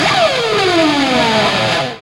Index of /90_sSampleCDs/Roland LCDP02 Guitar and Bass/GTR_GTR FX/GTR_Gtr Hits 1